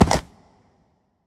Minecraft Version Minecraft Version 1.21.5 Latest Release | Latest Snapshot 1.21.5 / assets / minecraft / sounds / mob / horse / skeleton / water / jump.ogg Compare With Compare With Latest Release | Latest Snapshot
jump.ogg